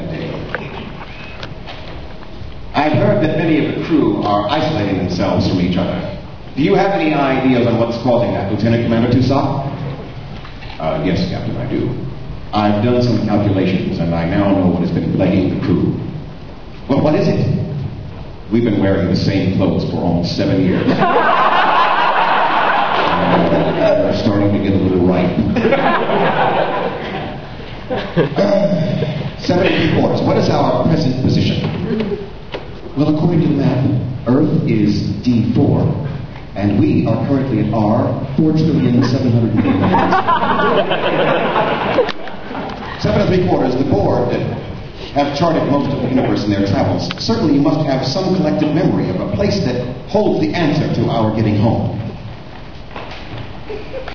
Now, then, came the high-point (for us Trekkers) of the day: the interview with Tim Russ!